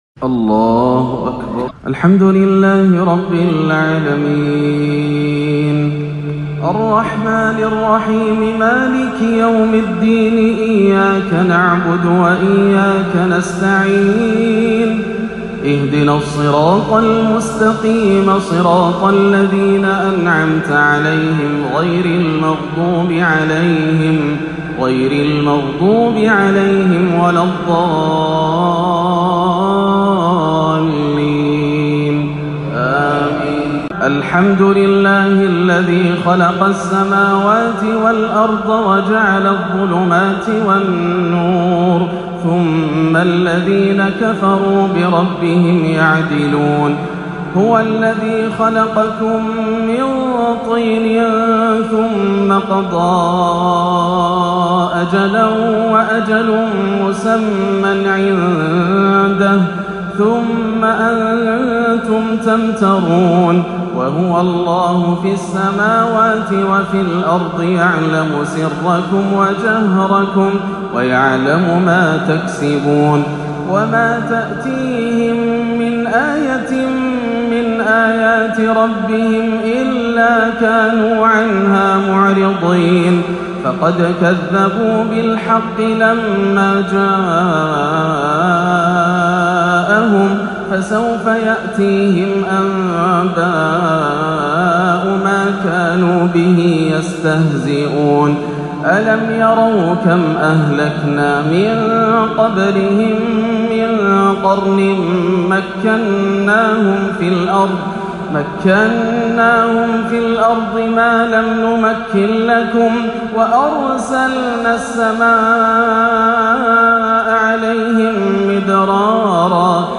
(من يصرف عنه يومئذ فقد رحمه) آيات عظيمة وأداء مبكي في عشائية لا تنسى - الخميس 13-11 > عام 1439 > الفروض - تلاوات ياسر الدوسري